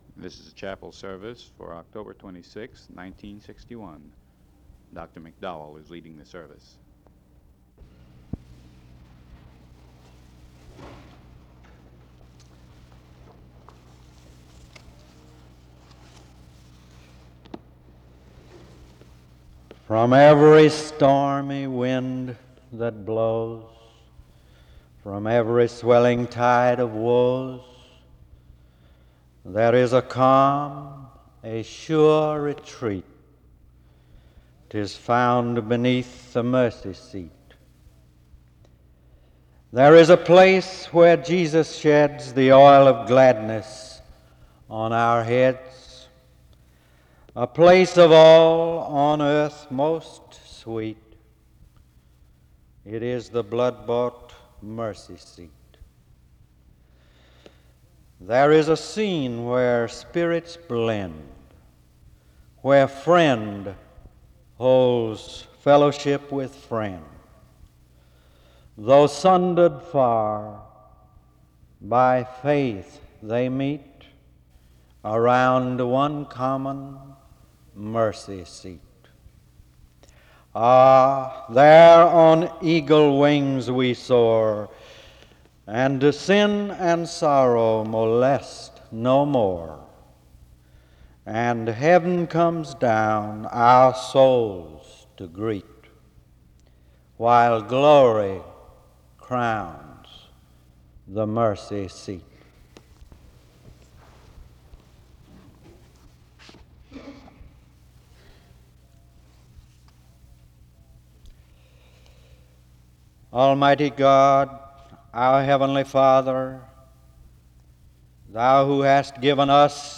There is a hymn played from 7:19-13:03
Location Wake Forest (N.C.)
SEBTS Chapel and Special Event Recordings SEBTS Chapel and Special Event Recordings